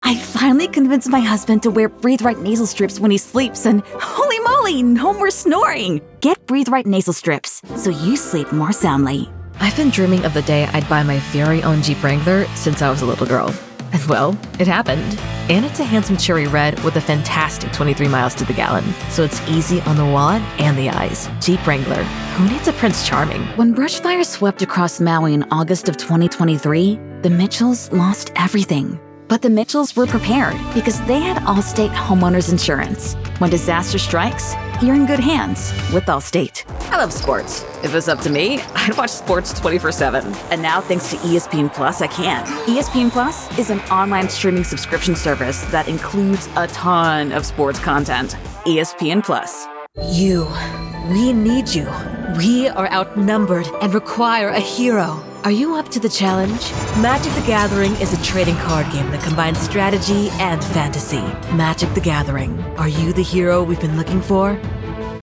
Location: Gautier, MS, USA Languages: english vietnamese Accents: standard us | natural Voice Filters: VOICEOVER GENRE ANIMATION 🎬 COMMERCIAL 💸